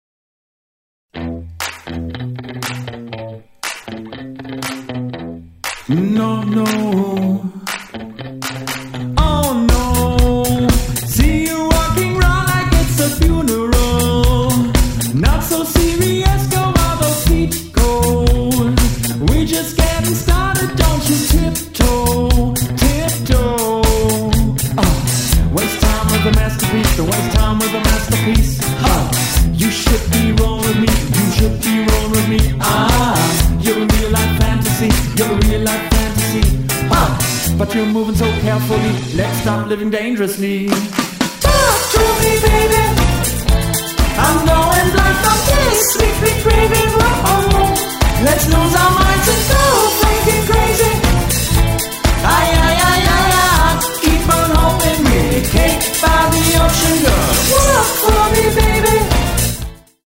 Livemusik | Rock | Charts